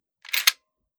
Foley / 30-30 Lever Action Rifle - Unloading 001.wav